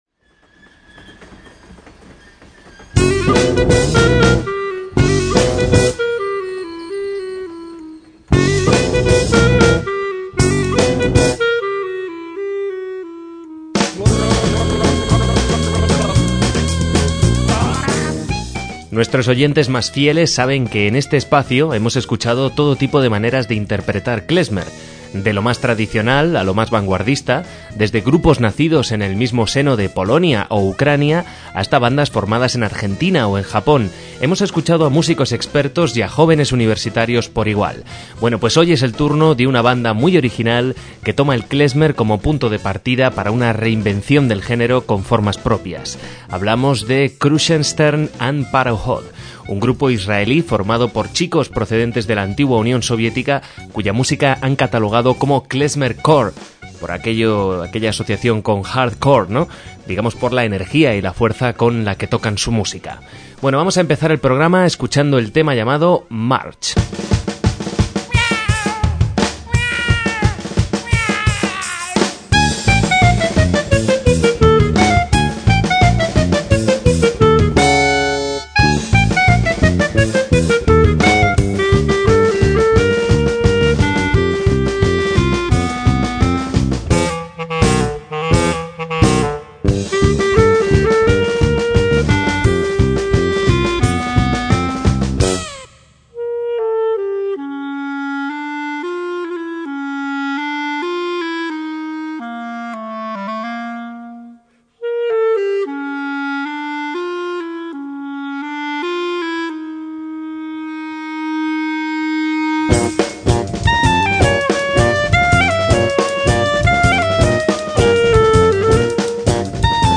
MÚSICA KLEZMER
klezmer-rock
bajo, voz
en clarinete